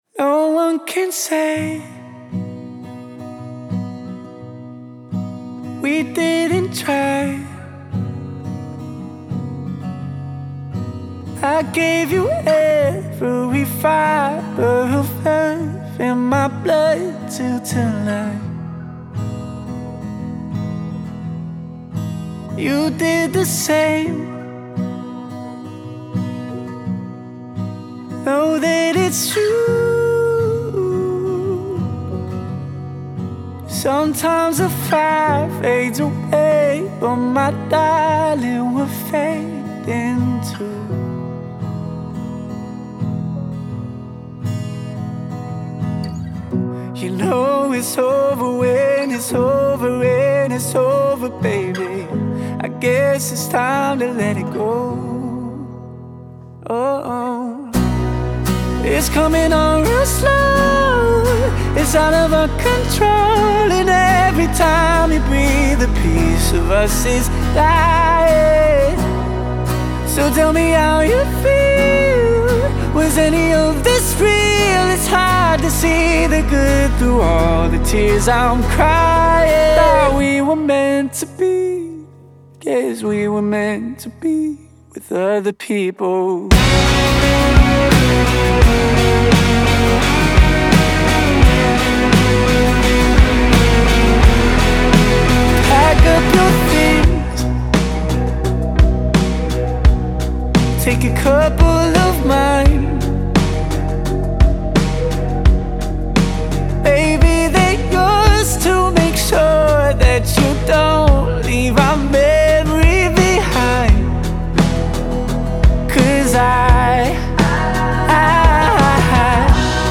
ژانر راک